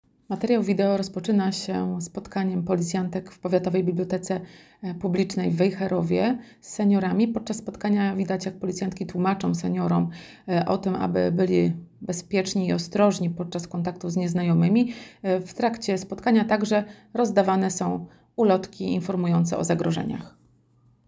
Nagranie audio Wejherowo_bezpieczny_senior_spotkanie_1_.m4a